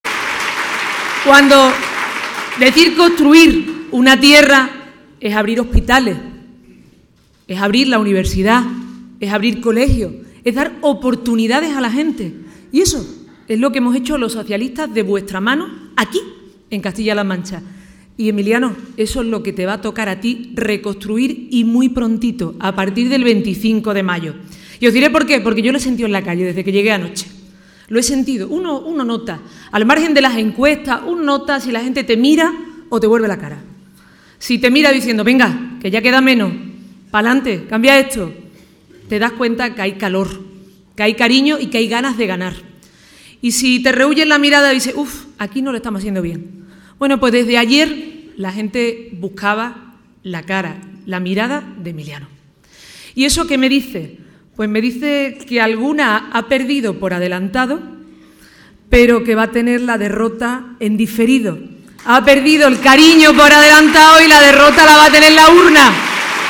Audio Susana Díaz acto PSOE Toledo